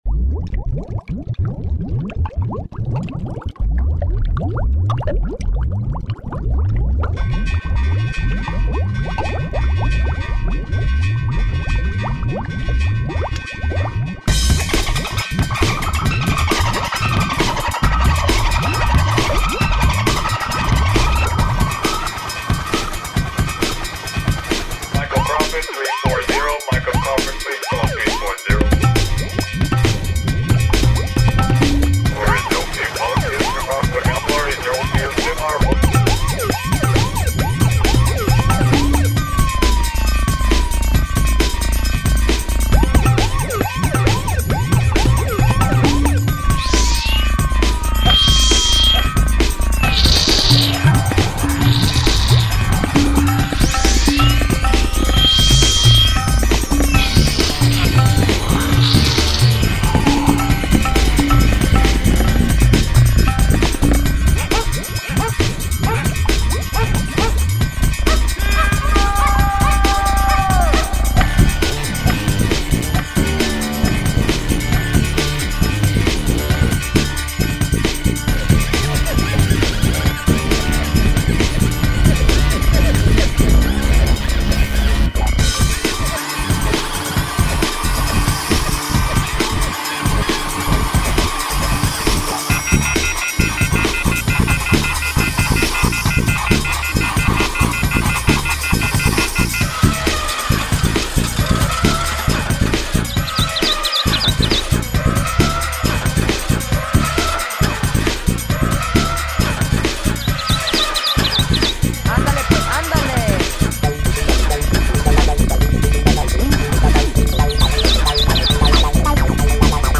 Love the open with the bubbles mixed with the dinner triangle.  And loved the apes at the end. Good use of thunderstorm as break.  Interesting use of ping pong sound for the last part of piece.